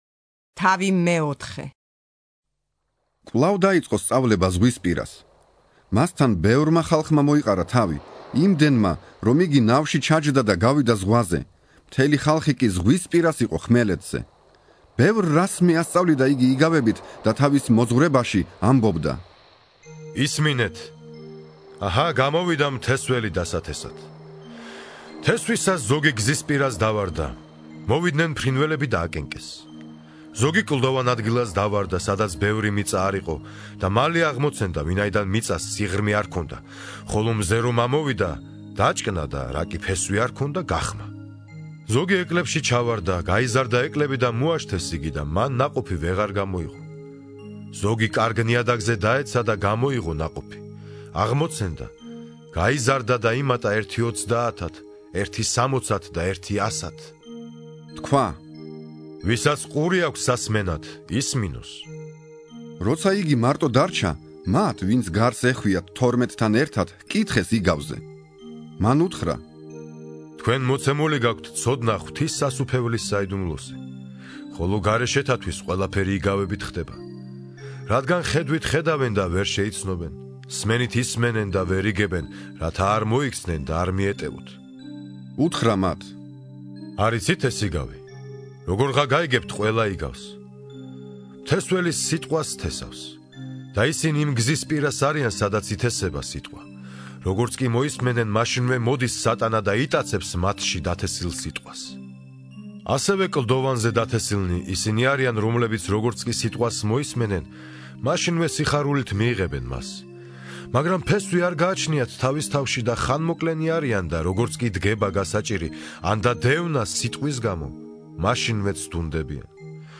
(41) ინსცენირებული ახალი აღთქმა - მარკოზის სახარება